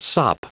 Prononciation du mot sop en anglais (fichier audio)
Prononciation du mot : sop